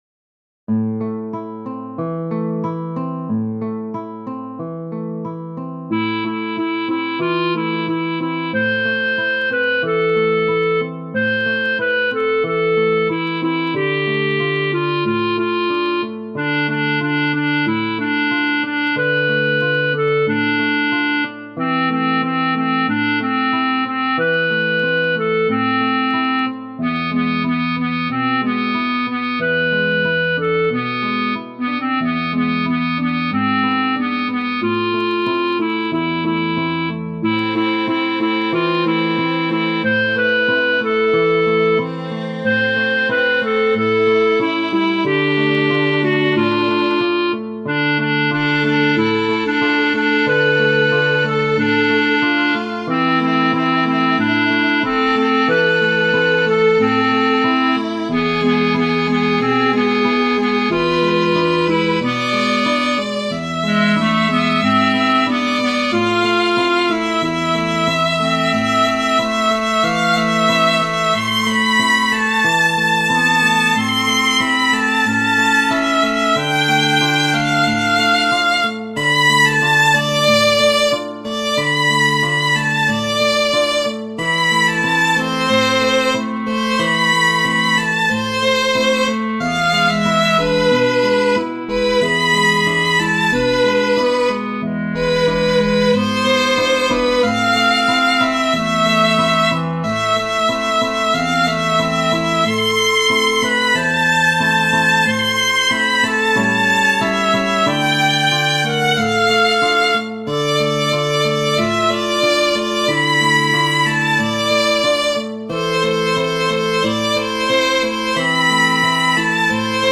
Ноты для гитара,скрипка,кларнет